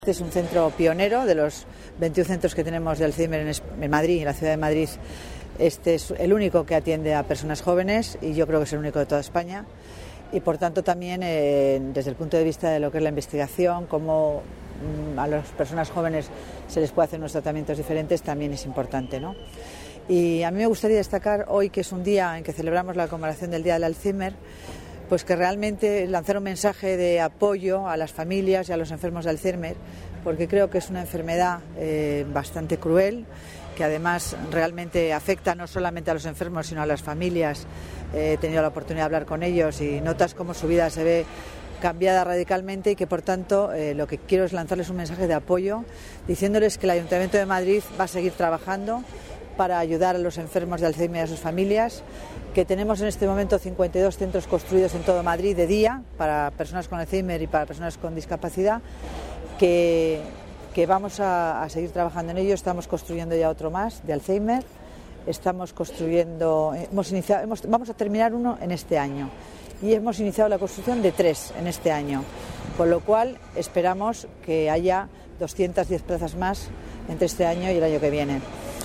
Nueva ventana:Declaraciones de la delegada de Familia y Servicios Sociales, Concepción Dancausa.